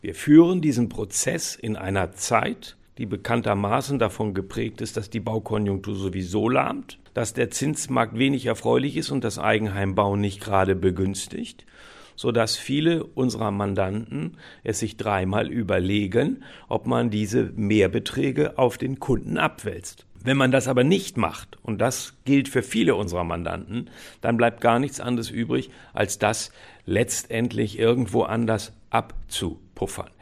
O-Ton: Willkür macht Hausbau teurer